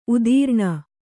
♪ udīrṇa